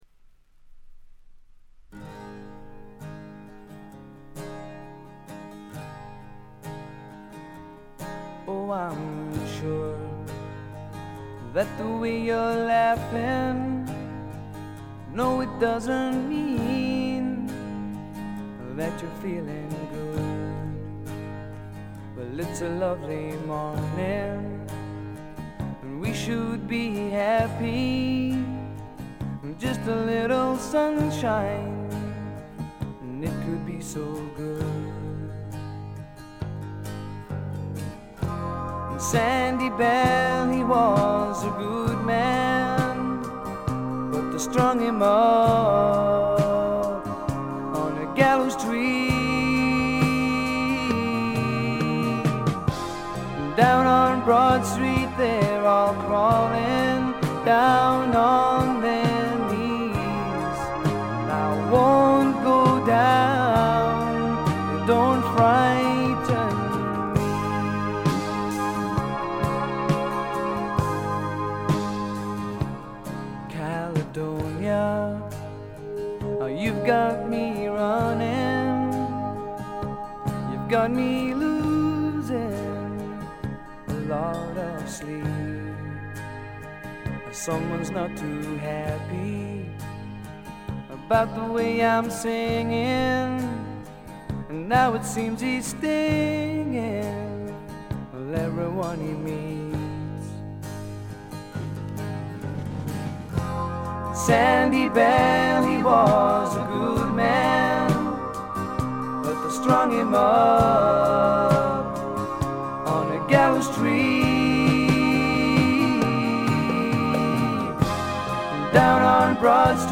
ほとんどノイズ感無し。
試聴曲は現品からの取り込み音源です。
Recorded At Pace Studios, Milton Keynes